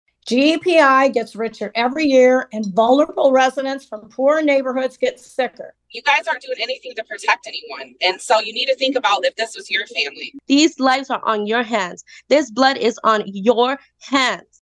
KALAMAZOO, MI (WKZO AM/FM) – A public hearing on a consent agreement for modifications at Graphic Packaging turned into a protest against the paperboard recycler and state environment officials last night.
Most of the northside residents who spoke at the event said Department of Environment, Great Lakes and Energy officials must do more to reduce the emissions, or shut down the plant.